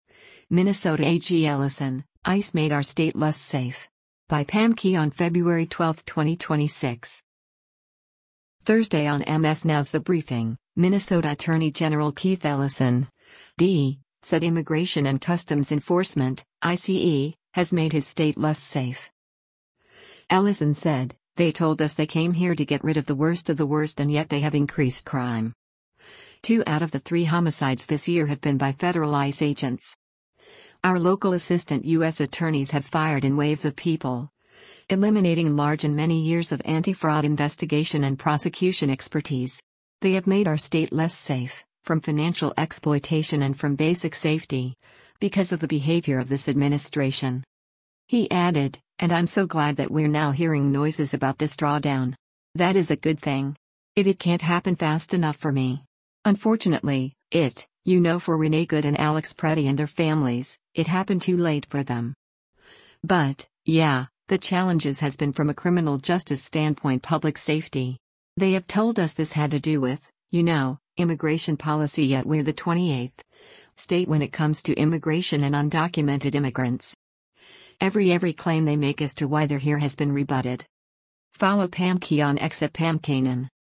Thursday on MS NOW’s “The Briefing,” Minnesota Attorney General Keith Ellison (D) said Immigration and Customs Enforcement (ICE) has made his state less safe.